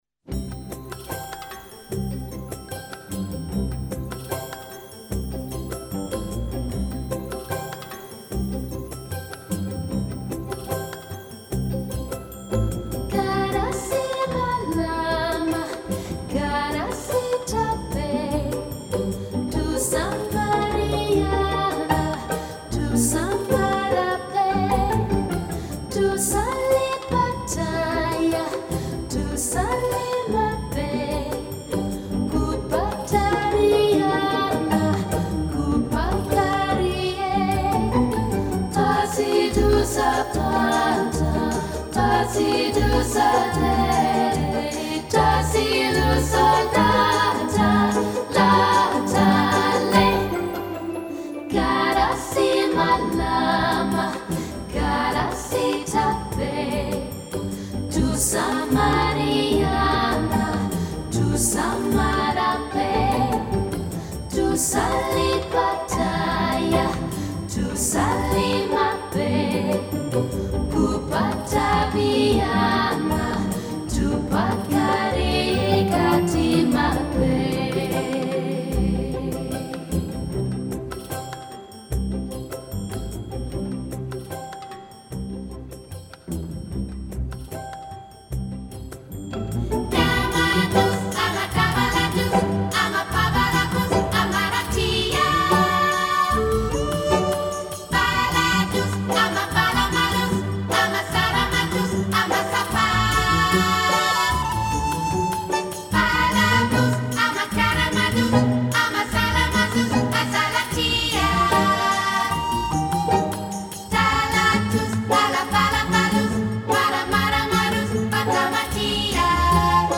New Age or Smooth Jazz